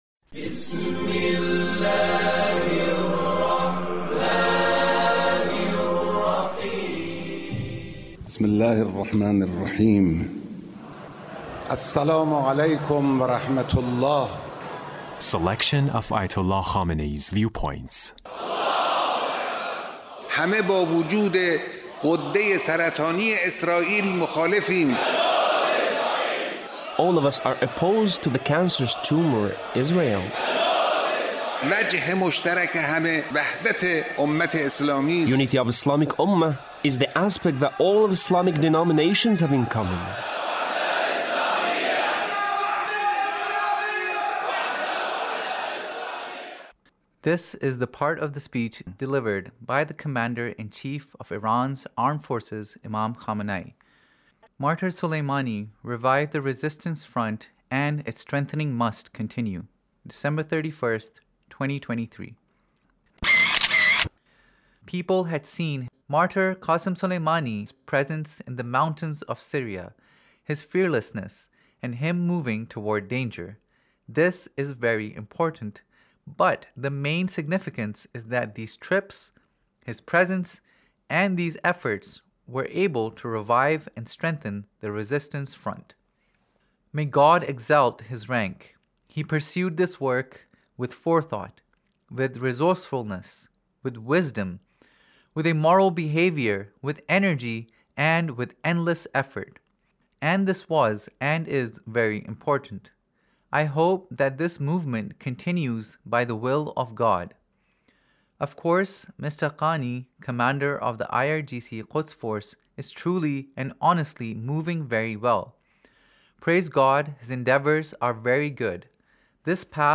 Leader's Speech in a meeting with Martyr Soleimani's Family